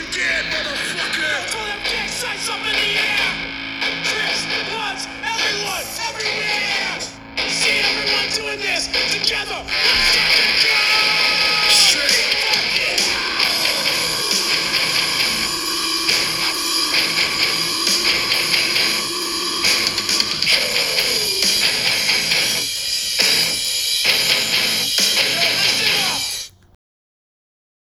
Category: Sound FX   Right: Personal
Tags: beat down